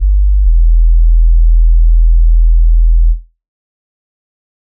808s
Bass Funk 1.wav